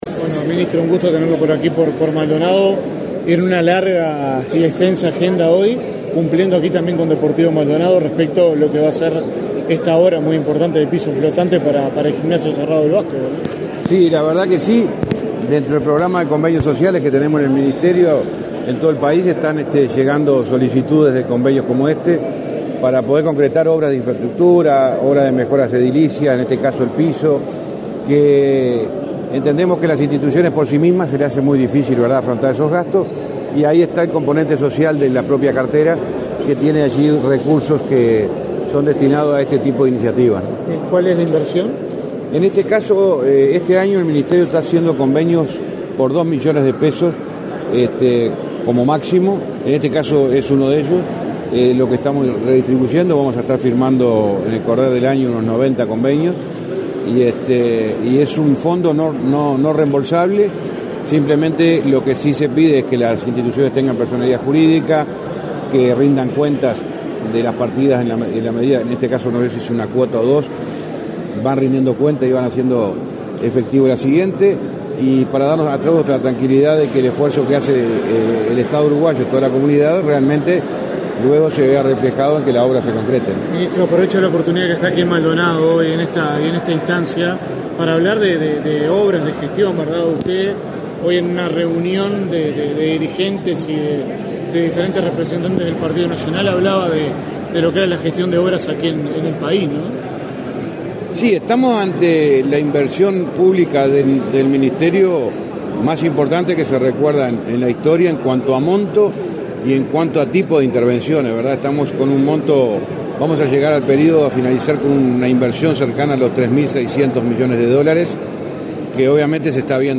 Declaraciones a la prensa del ministro del MTOP, José Luis Falero
Falero prensa.mp3